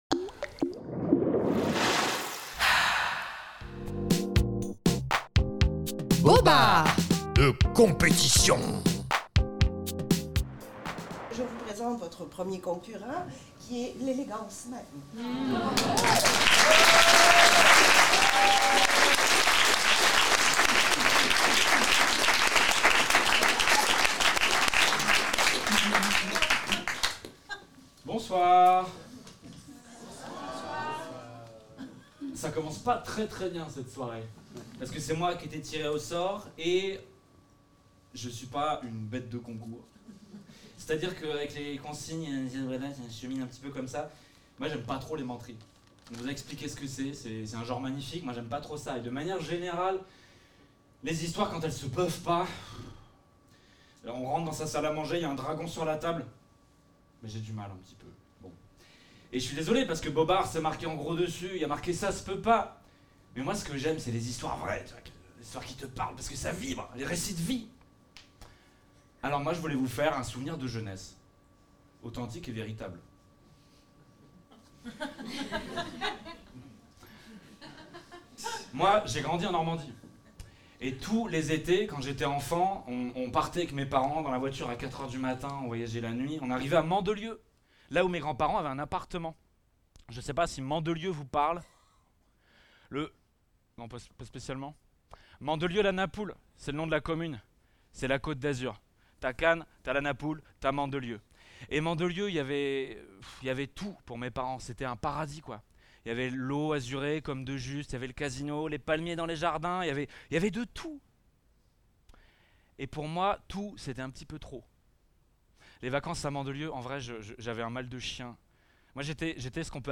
À écouter : Mandelieu, un souvenir de jeunesse véritable, raconté au festival Bobards 2024 au Grand Lieu du Conte